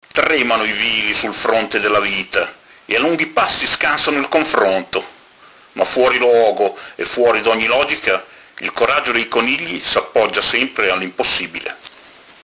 legge le sue poesie